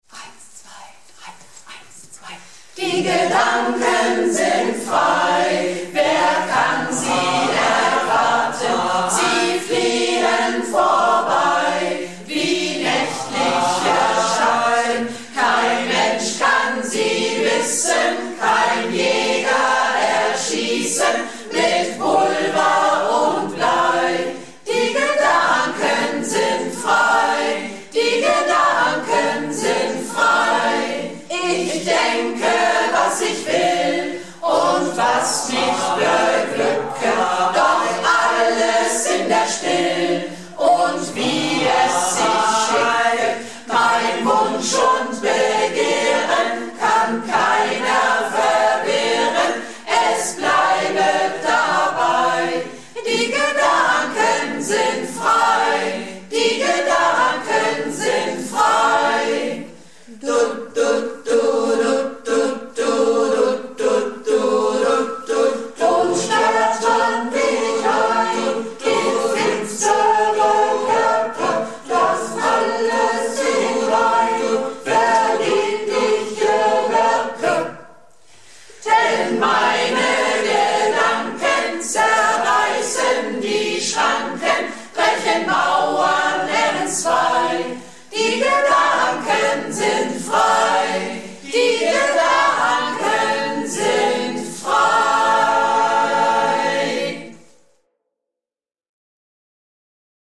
Recha Freier-Projektchor - Probe am 17.01.17